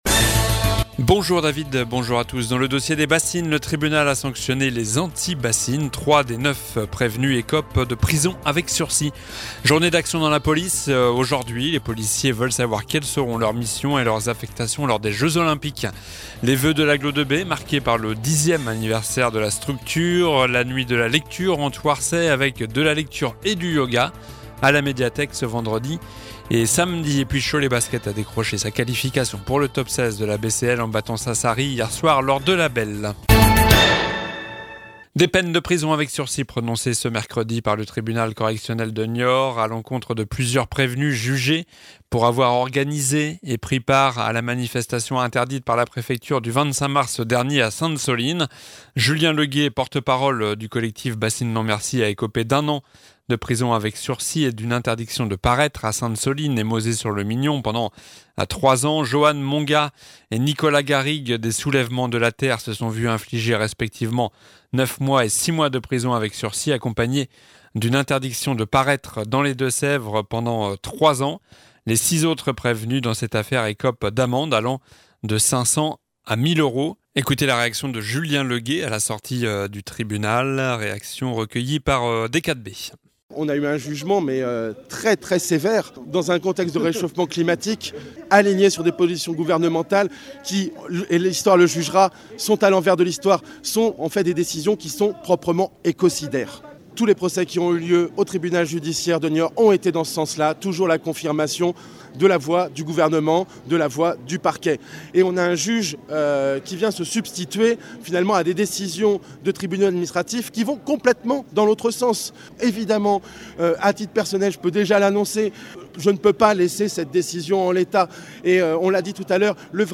Journal du jeudi 18 janvier (midi)